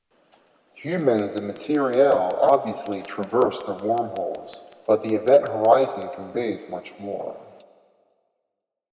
The sound the played was an audio clip from the show Star Gate which read the following:
Also in the clip was audible noise. A signal!
There are 27 positions of data which is odd for computer signals to not have an even number.